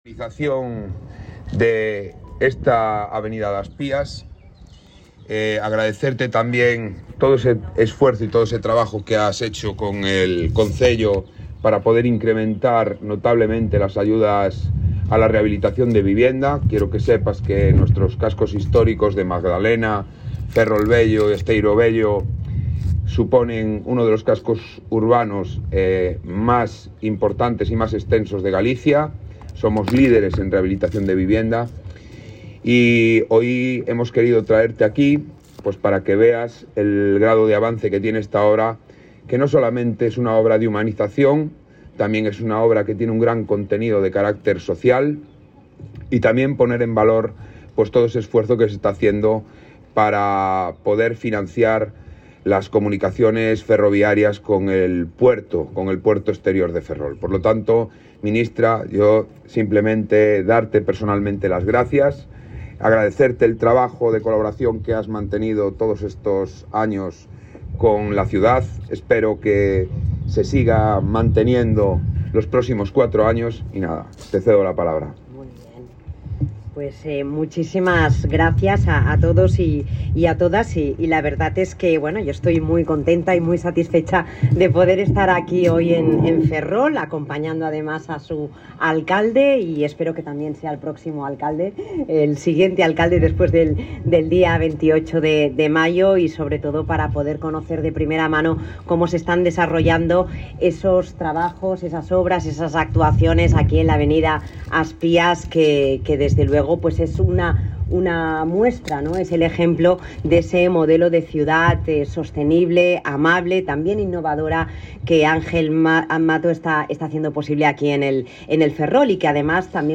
Se adjuntan los audios de la Ministra Raquel Sánchez y Ángel Mato en Ferrol